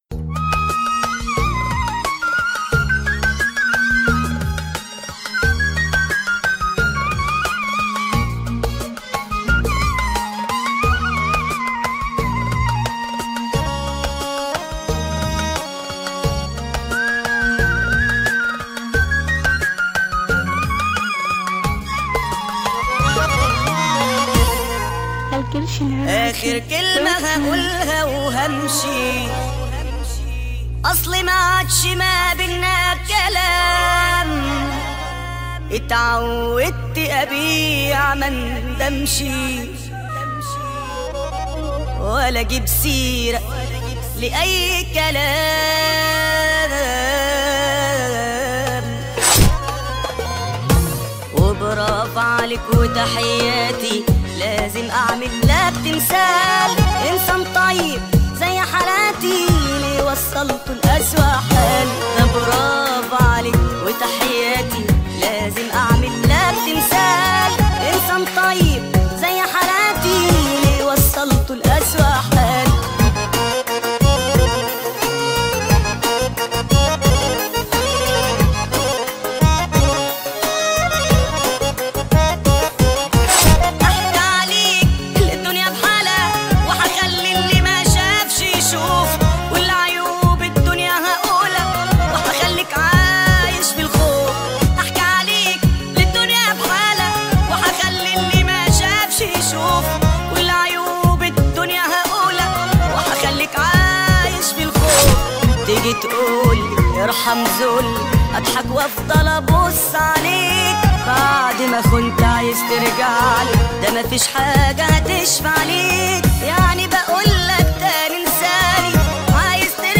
اغاني مصريه حزينه